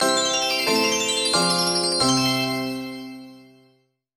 На этой странице собраны энергичные звуки победы и выигрыша — от фанфар до коротких мелодичных оповещений.
Звук победы в бинго